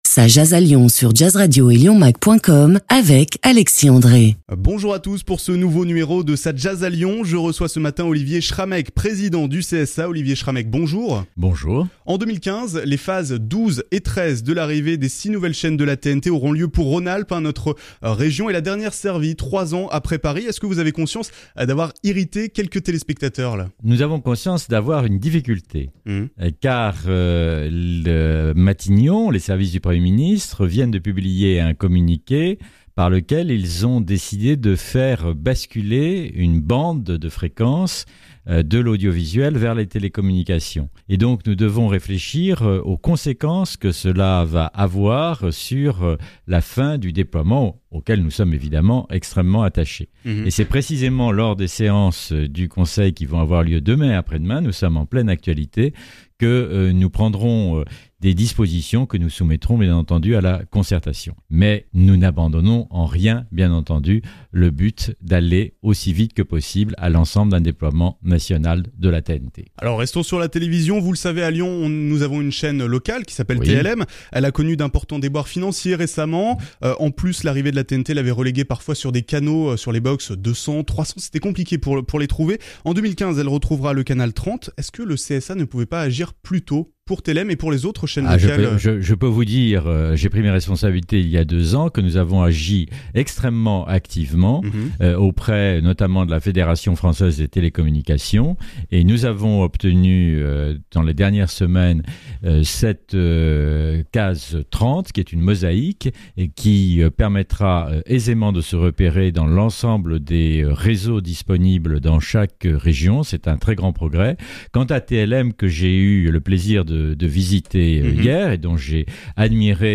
Olivier Schrameck au micro de Jazz Radio